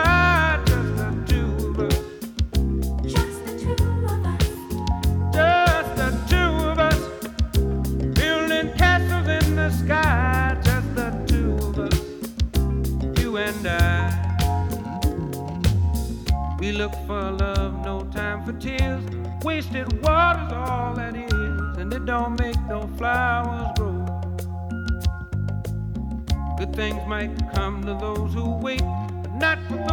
• R&B/Soul